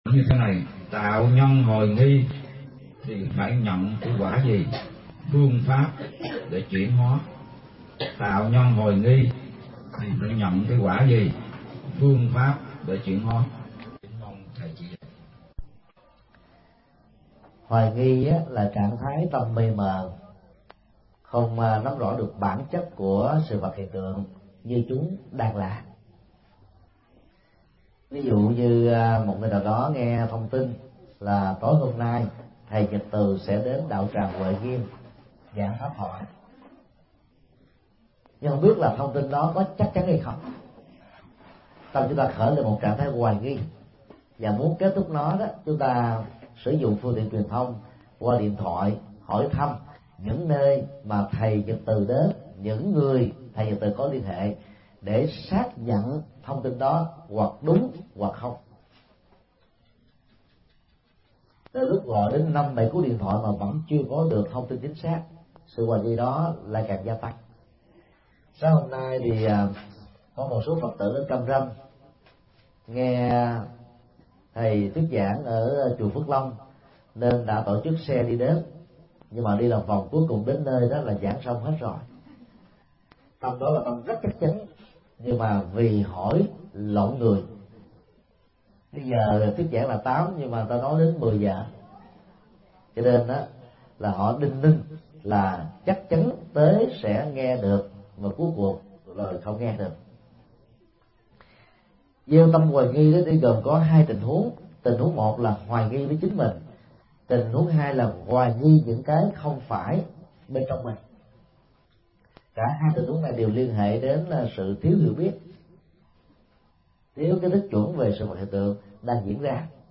Vấn đáp: Tâm lý hoài nghi